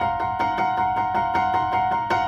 Index of /musicradar/gangster-sting-samples/105bpm Loops
GS_Piano_105-C1.wav